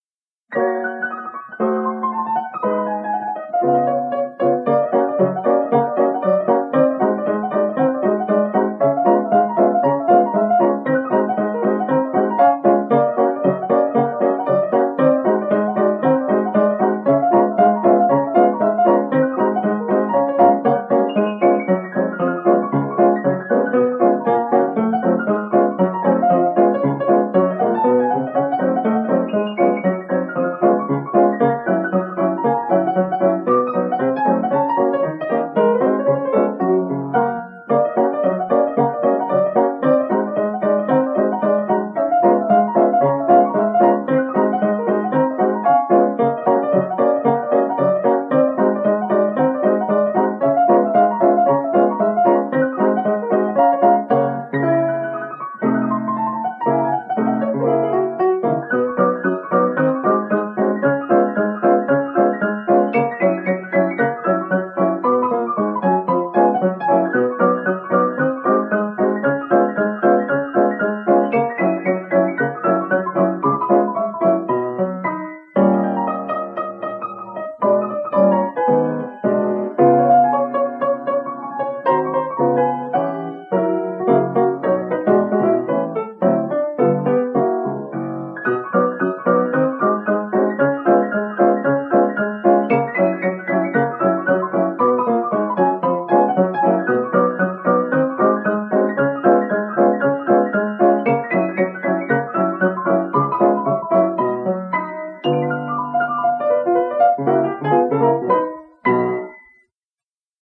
An original rag